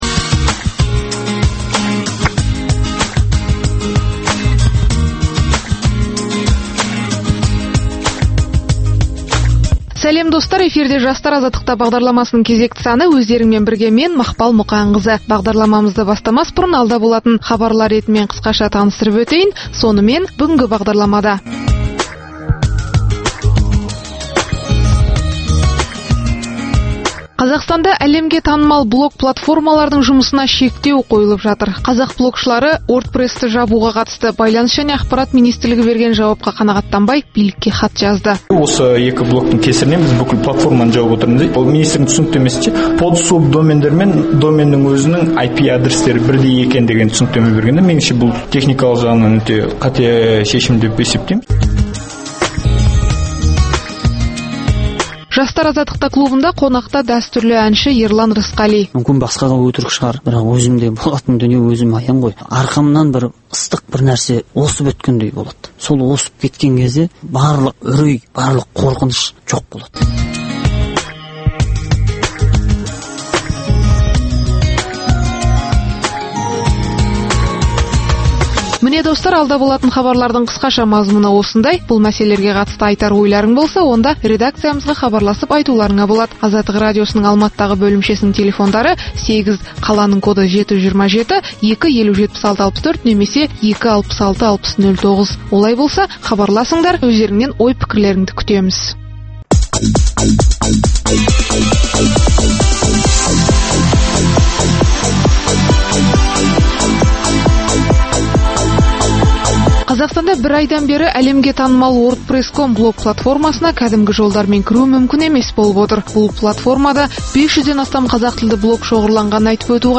Пікірталас клубы